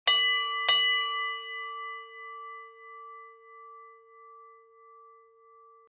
Звук гонга в боксе два удара